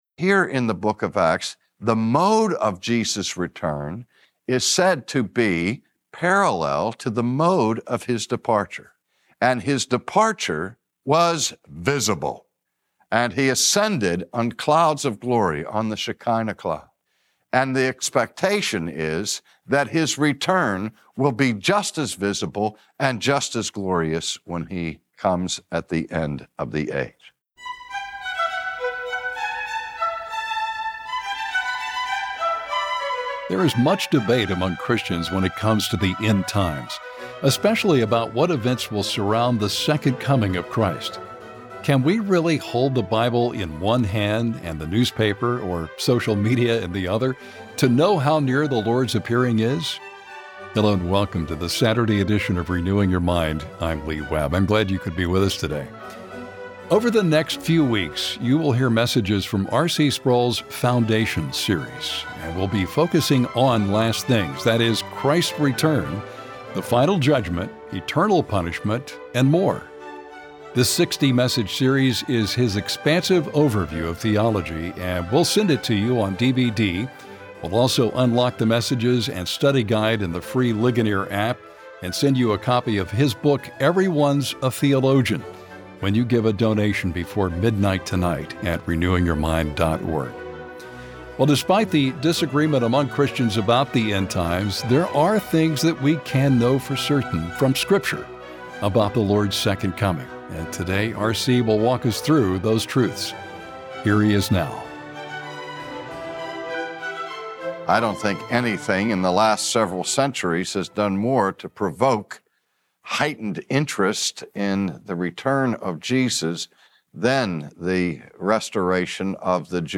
Today, R.C. Sproul presents three details that we know for certain about the second coming of Christ.